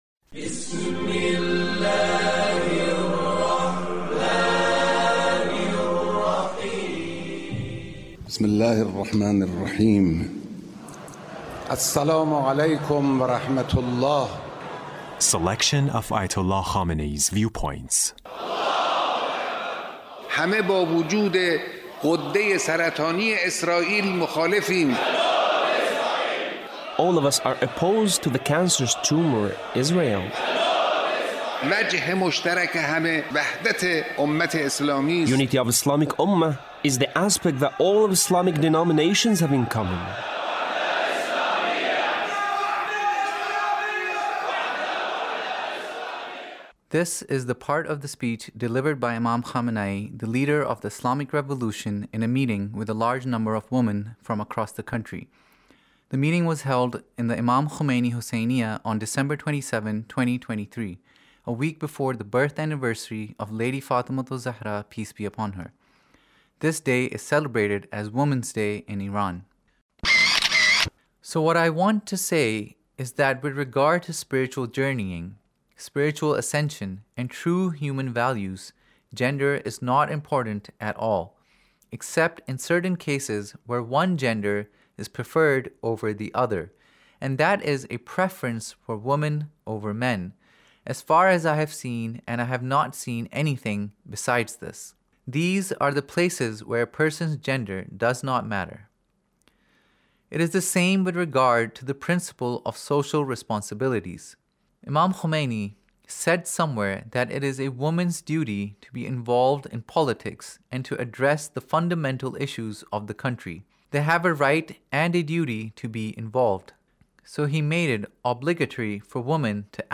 Leader's Speech in a meeting with Ladies on Women's Day